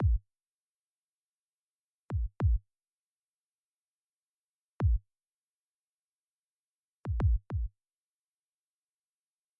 Бас-бочка.
• добавил в первом такте синкопу длительностью 1/8, ведущую во второй такт;
Следует отметить, что синкопы и «эхо» сыграны с громкостью ¾ от основной.
К бас-бочке я применил эквалайзер с поднятым диапазоном 40-100 Гц и немного компрессировал её.